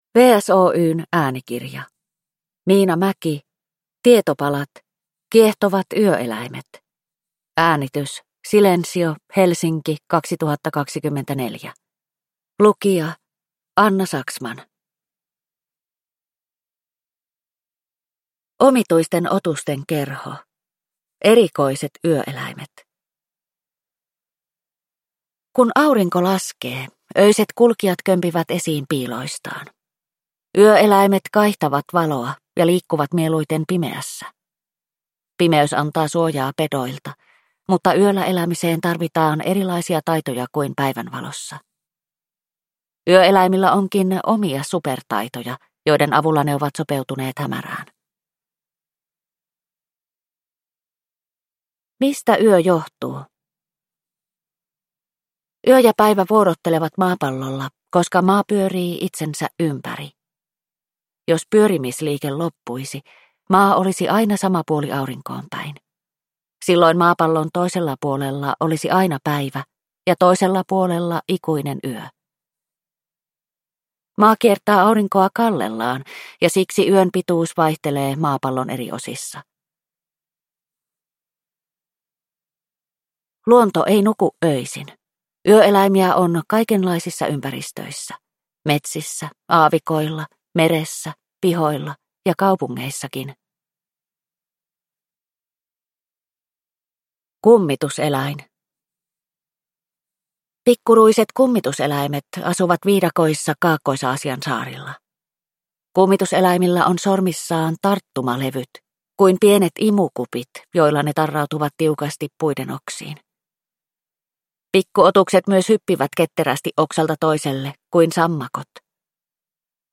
Tietopalat: Kiehtovat yöeläimet – Ljudbok